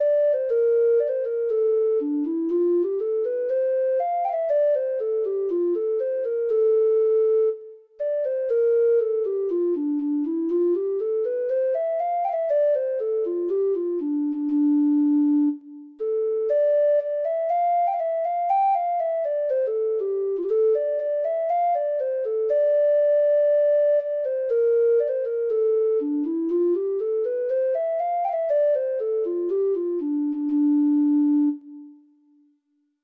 Free Sheet music for Treble Clef Instrument
Irish